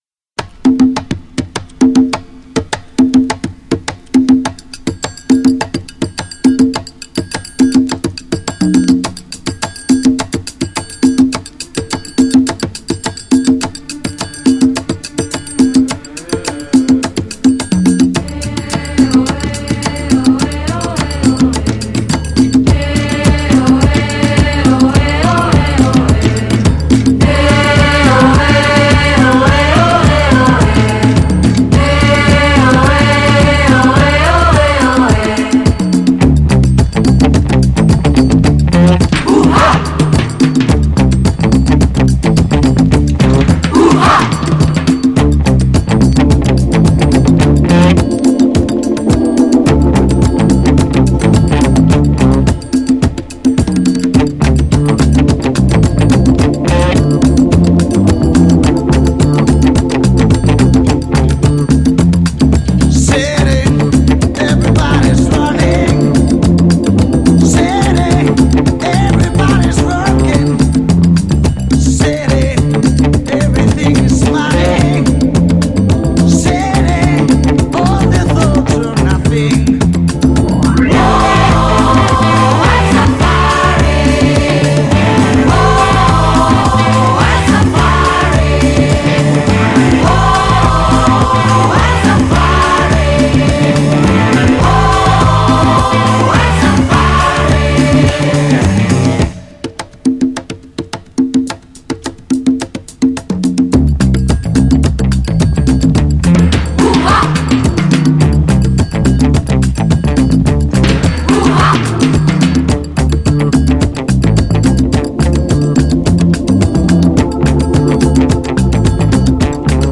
GENRE Dance Classic
BPM 121〜125BPM